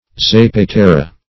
Search Result for " zapatera" : The Collaborative International Dictionary of English v.0.48: Zapatera \Za`pa*te"ra\, n. [Sp. aceituna zapatera.] (Olive trade) A cured olive which has spoiled or is on the verge of decomposition; loosely, an olive defective because of bruises, wormholes, or the like.